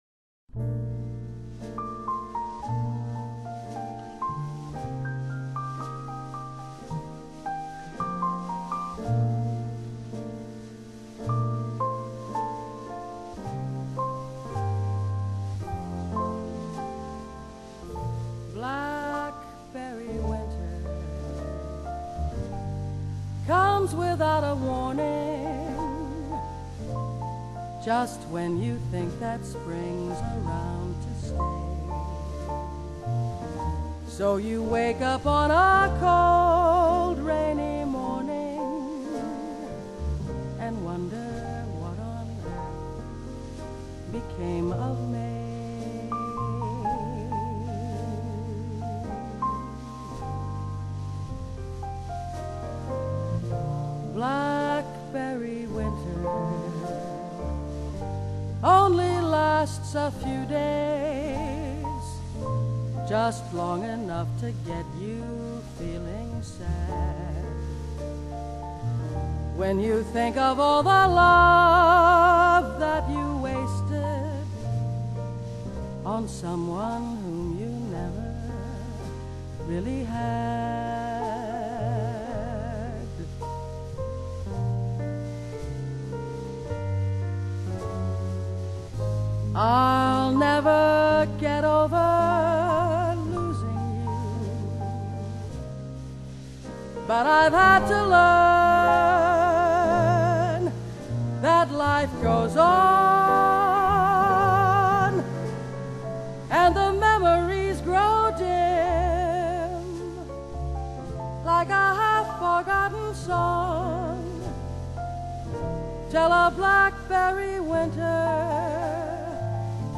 Vocall Jazz